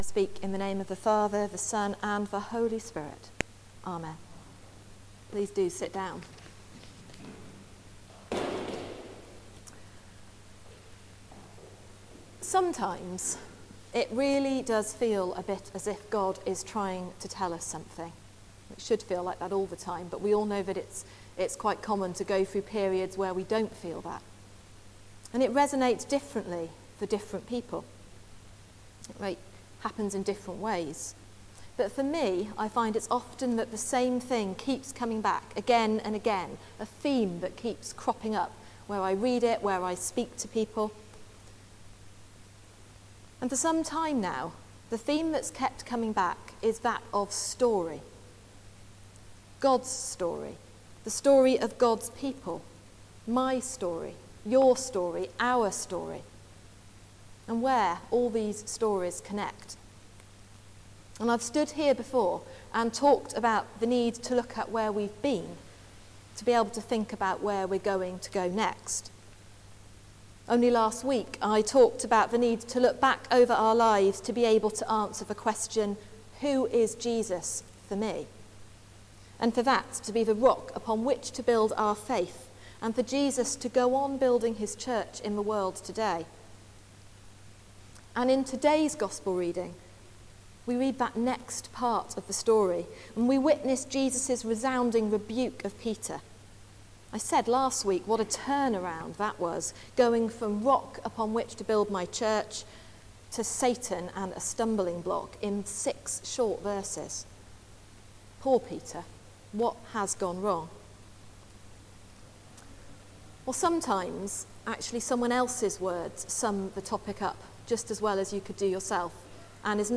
Sermon: Storytelling God | St Paul + St Stephen Gloucester